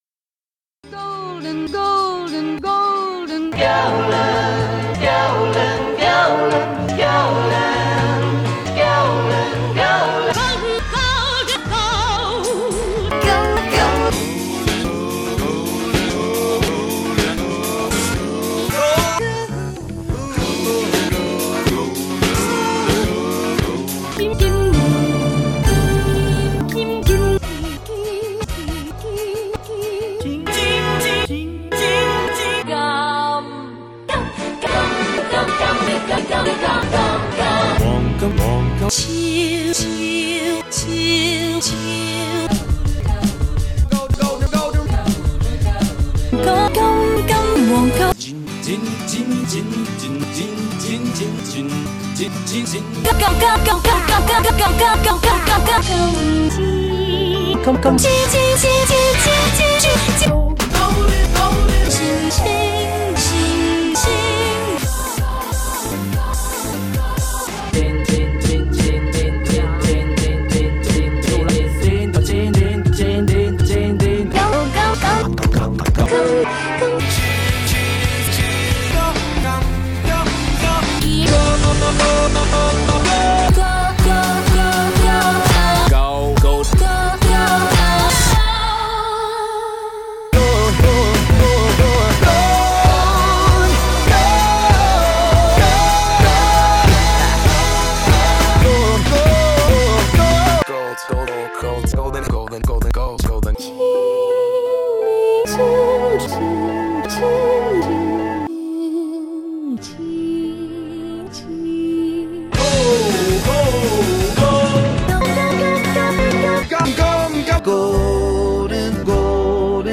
Songs I – V is a series of short sound pieces edited from sung instances of the word ‘golden’, to produce occasional audio interludes, at once plaintive, joyful and wistful.
Songs V, 2015, 3’25” was produced for the 1st Asia Biennial / 5th Guangzhou Triennial, Guangdong Museum of Art, with over 50 tracks nominated by participating artists and curators